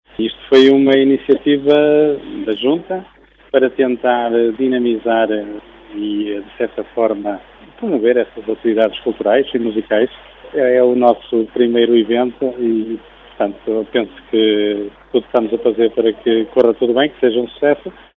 O autarca local, David Torres, apresenta os objectivos da iniciativa.